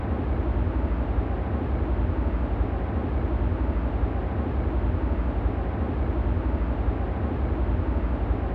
Propagation effects in the synthesis of wind turbine aerodynamic noise | Acta Acustica
Test cases C: xR = 500 m, medium turbulence and grass ground in summer.